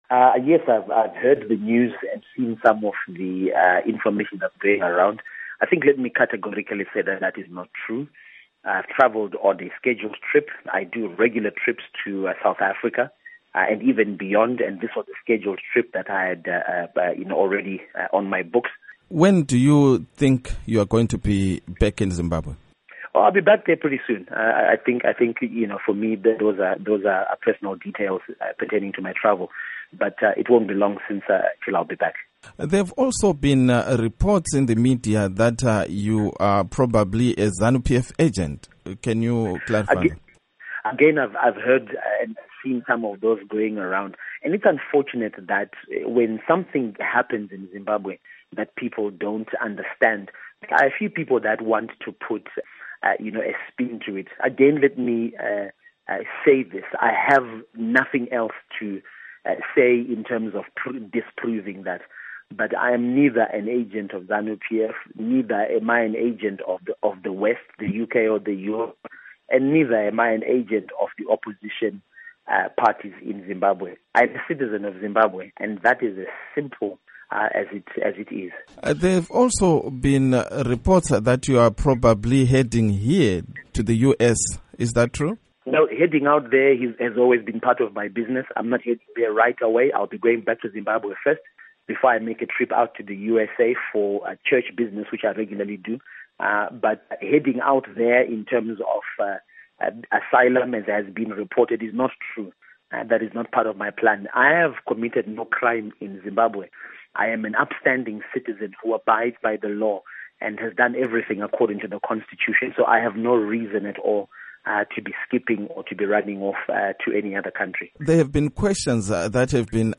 Interview With Evan Mawarire on Visiting South Africa, Other Nations